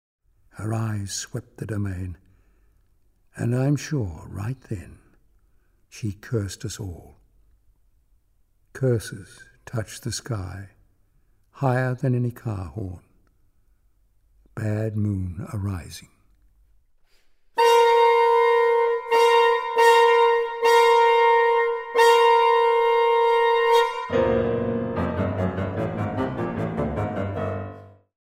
richly expressive woodwind palette